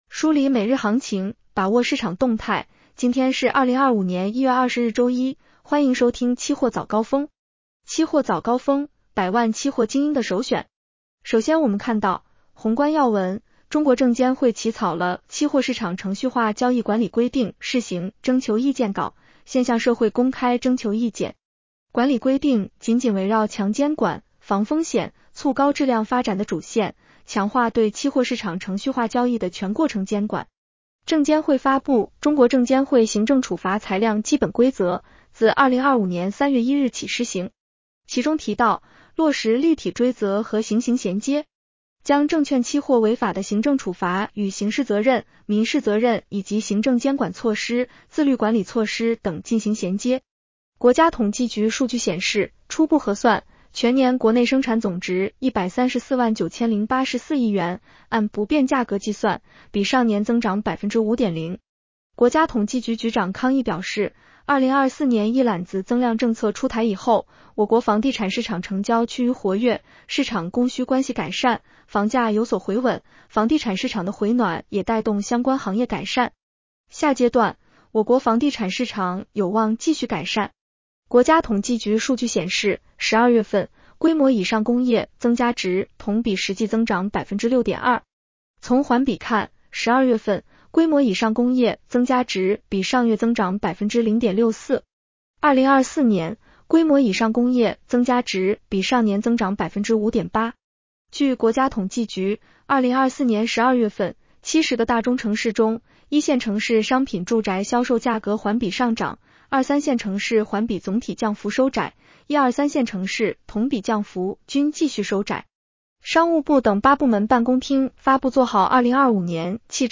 期货早高峰-音频版 女声普通&#…
期货早高峰-音频版 女声普通话版 下载mp3 宏观要闻 1.